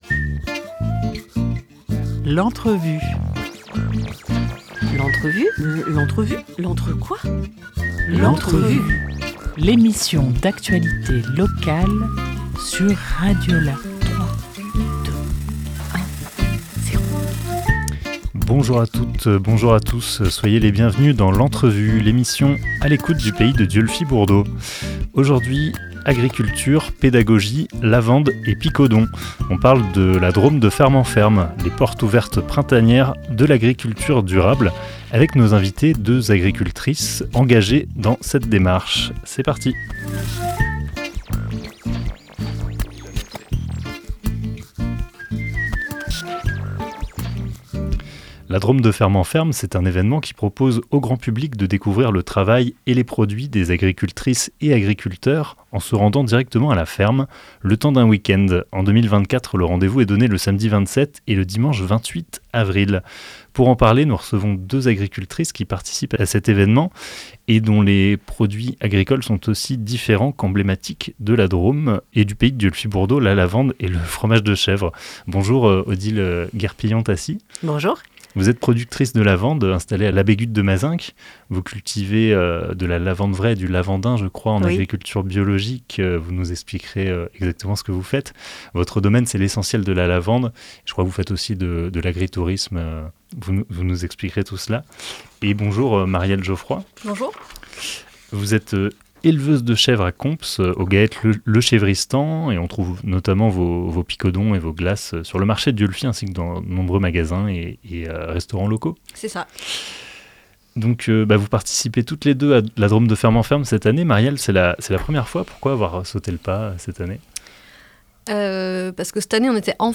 Pour en parler en parler, nous recevons deux agricultrices :
Interview